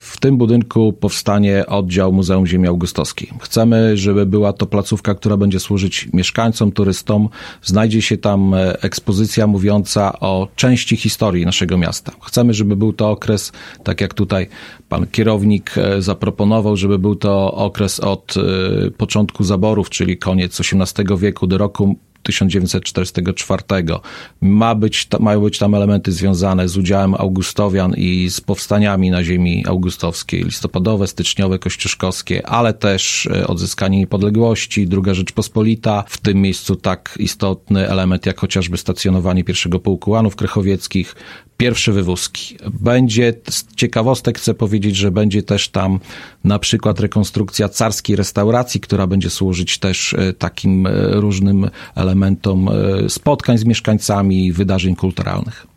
Sala dawnego baru ma być także miejscem imprez kulturalnych i spotkań mieszkańców. – mówi burmistrz.
burmistrz-clip-1-1.mp3